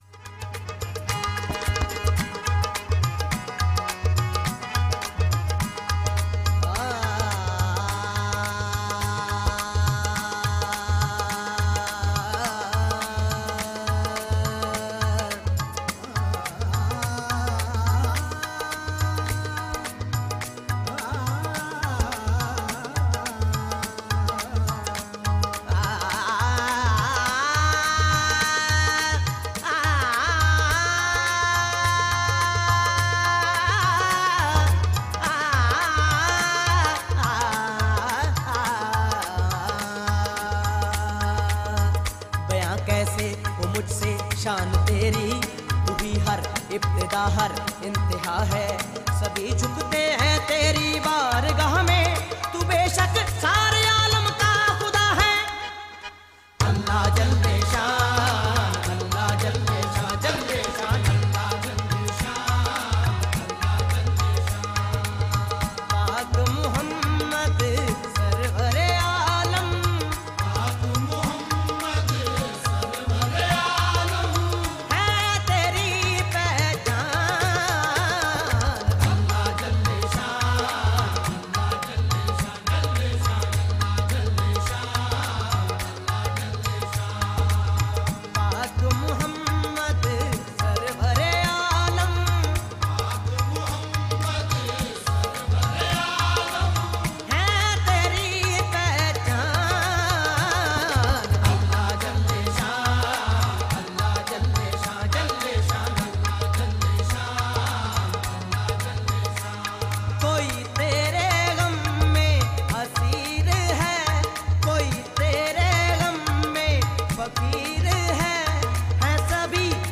Punjabi Qawwali and Sufiana Kalam